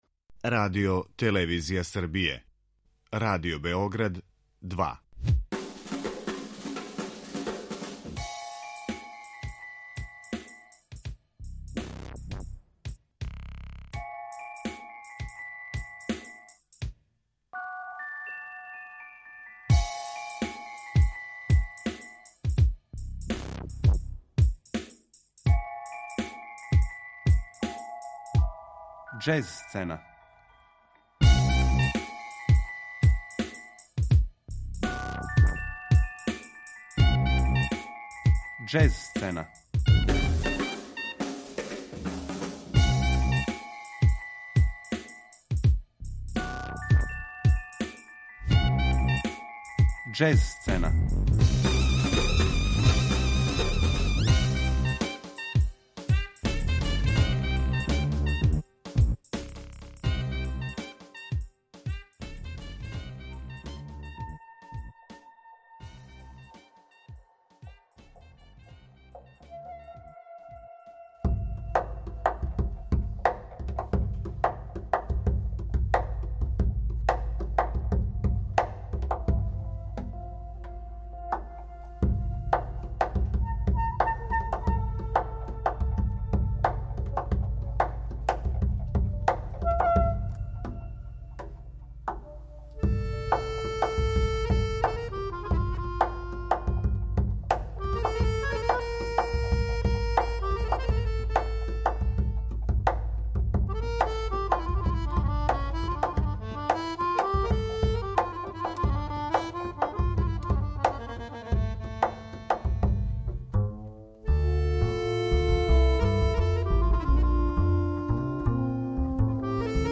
Џез сцена